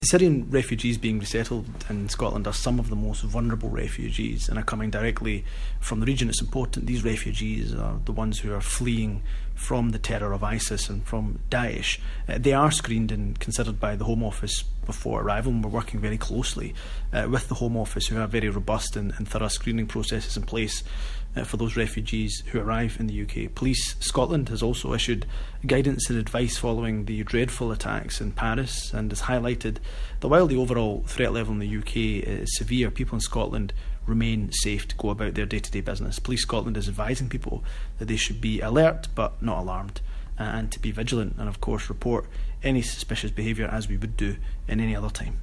Scotland's Minister for Europe, Humza Yousaf, tells us Syrian refugees coming to the UK are being properly vetted